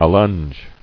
[al·longe]